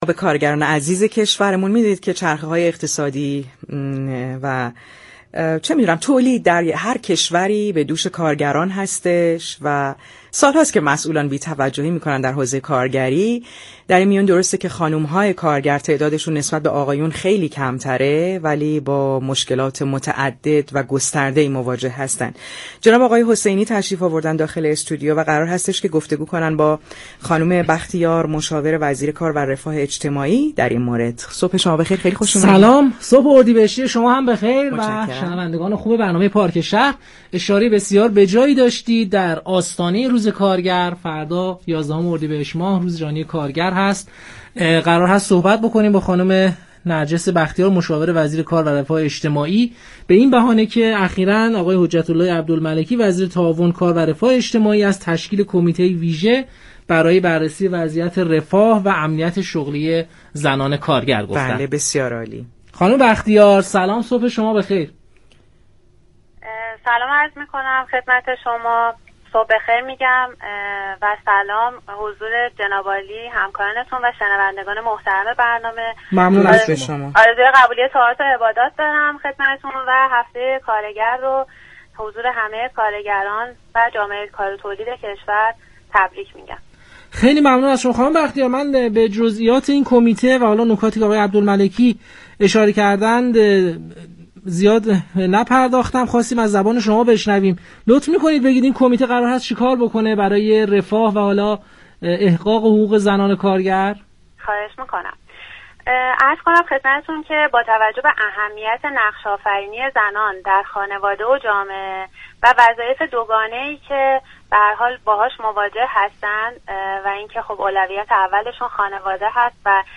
به گزارش پایگاه اطلاع رسانی رادیو تهران، نرجس بختیار مشاور وزیر تعاون، كار و رفاه اجتماعی در گفتگو با پارك شهر رادیو تهران در خصوص تشكیل كمیته ویژه رفاه و امنیت شغلی زنان كارگر، گفت: نقش‌آفرینی دوگانه زنان در خانواده و جامعه از اهمیت زیادی برخوردار است.